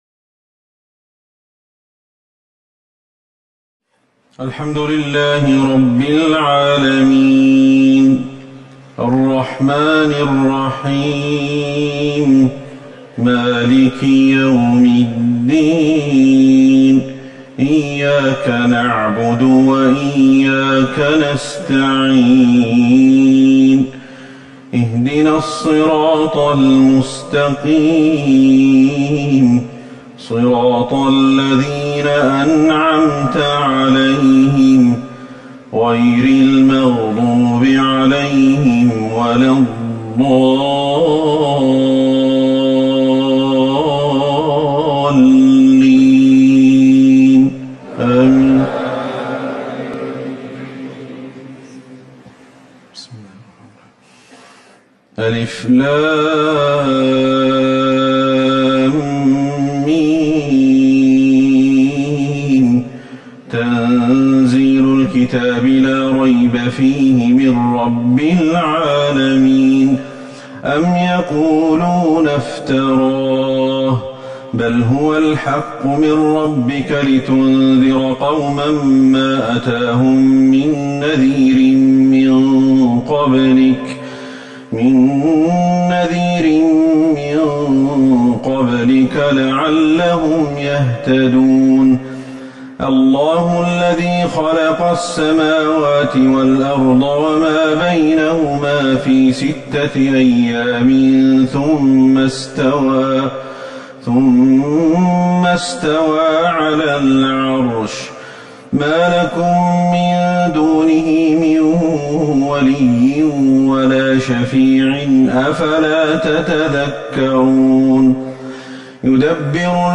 صلاة الفجر 8 جمادى الاولى 1441 سورتي السجدة و الانسان